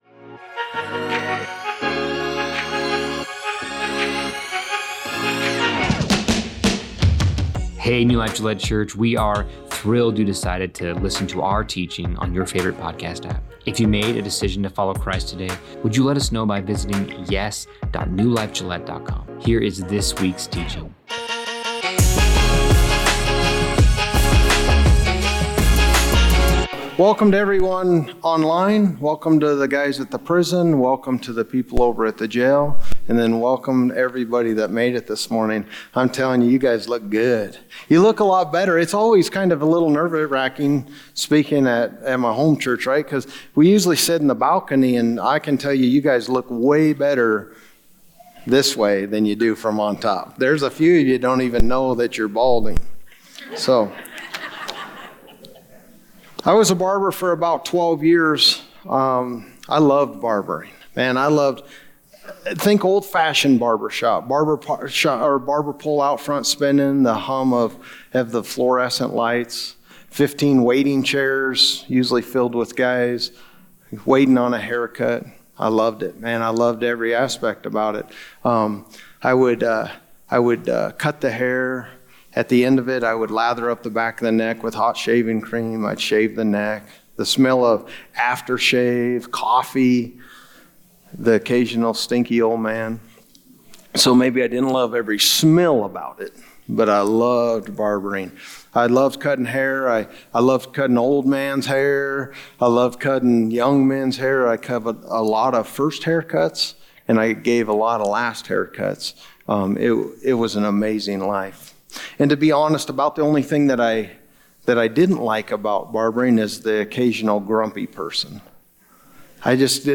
New Life Gillette Church Teachings Acts: Life On Mission - Week 9 - Why You Mad Bro?